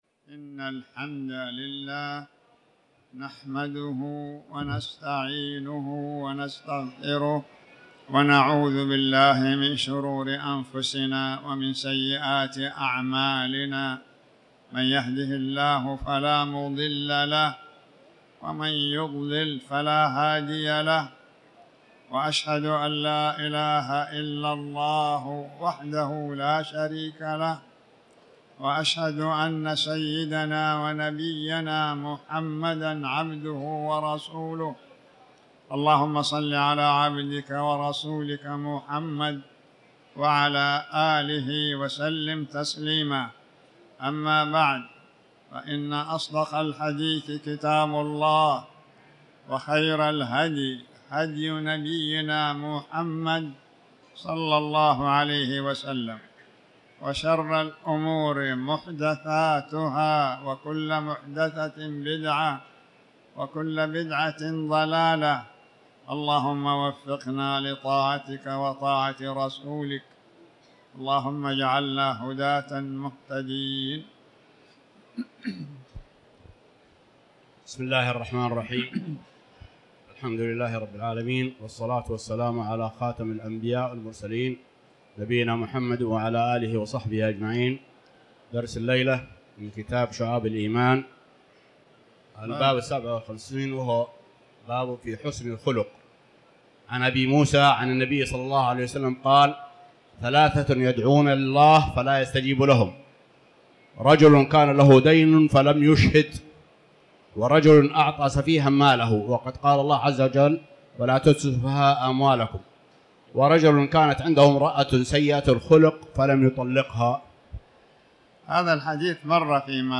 تاريخ النشر ٢٩ شوال ١٤٤٠ هـ المكان: المسجد الحرام الشيخ